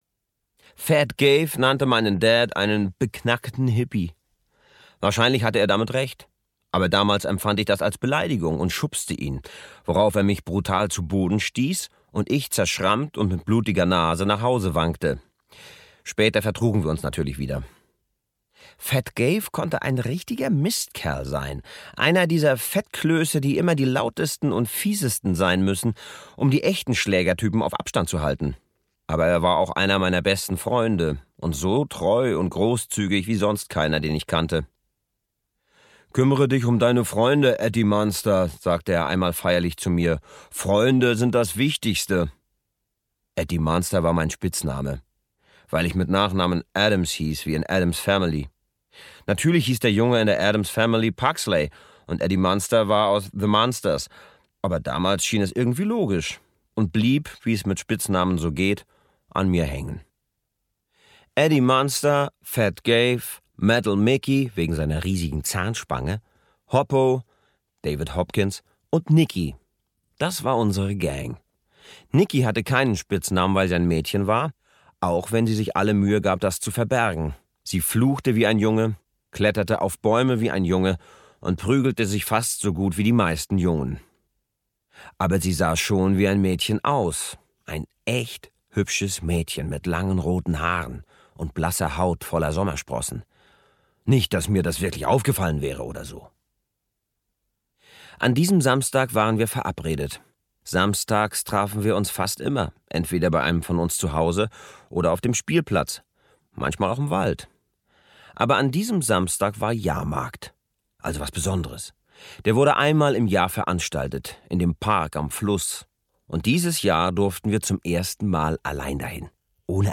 Der Kreidemann (DE) audiokniha
Ukázka z knihy
• InterpretDevid Striesow